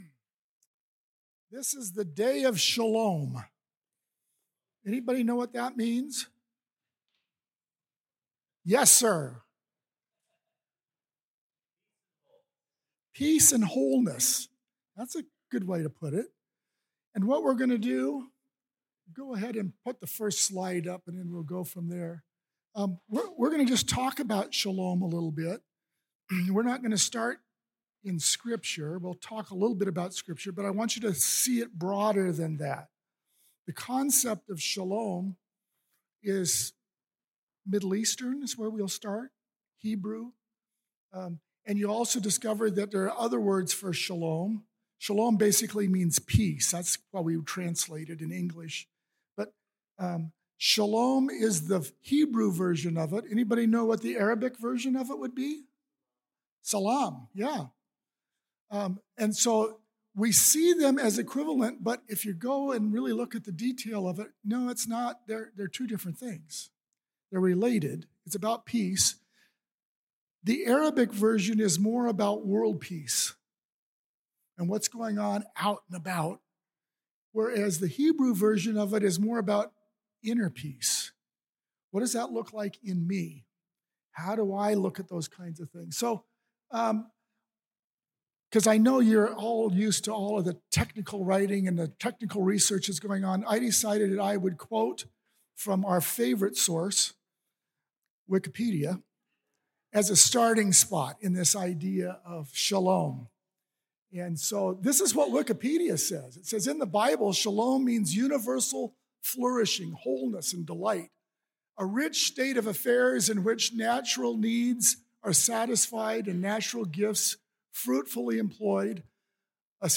This talk was given in chapel on Wednesday, October 30th, 2024 God Bless you.